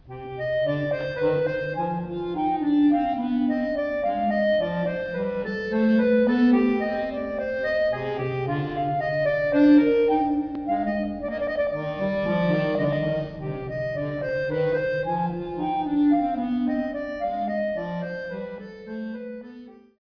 accordion, bayan